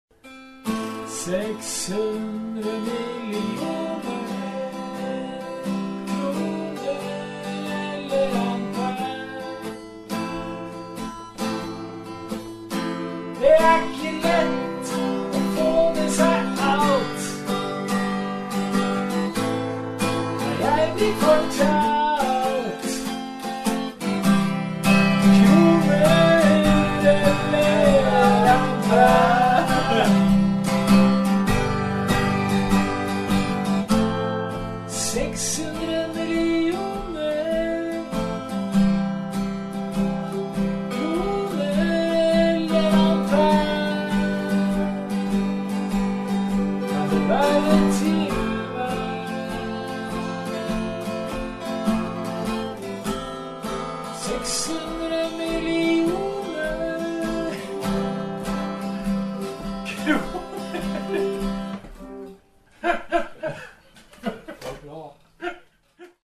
Detta var en fantastisk jam-session - fint flyt från första början till sista tonen. Bägge spelade 6-strängad akustisk gitarr